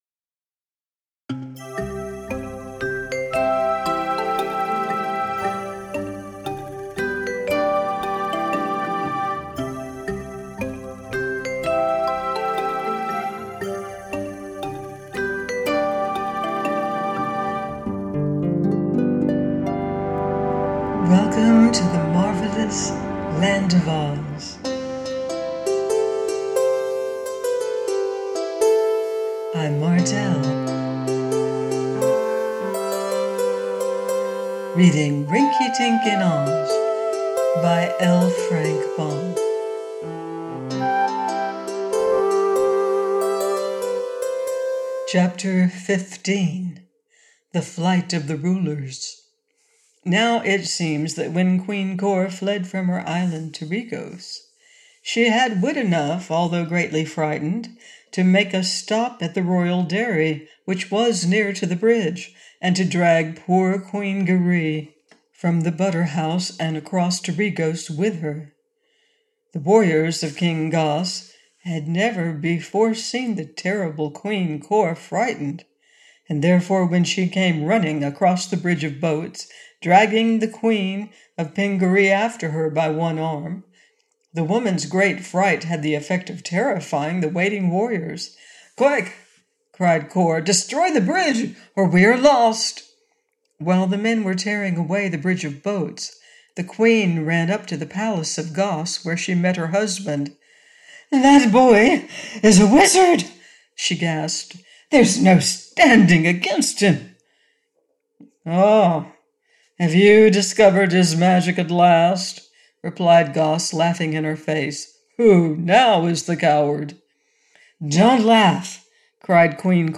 Rinkitink In Oz – by Frank L. Baum - AUDIOBOOK